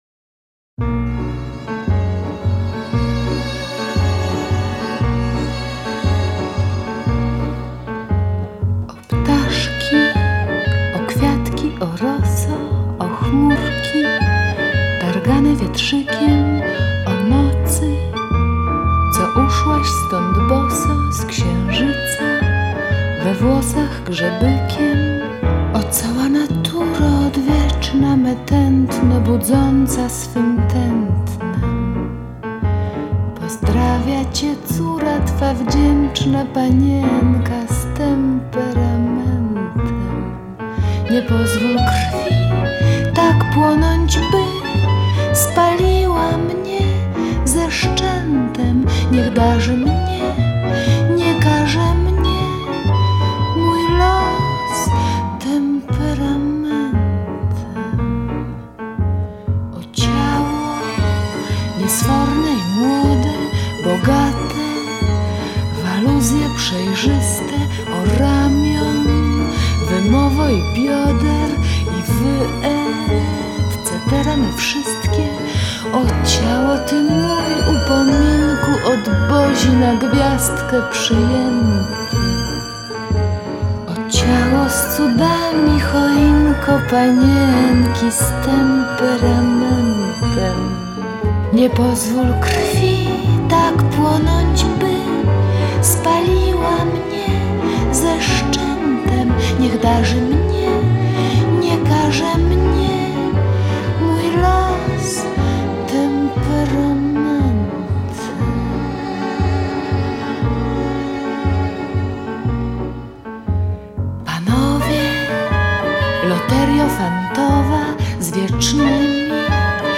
koncert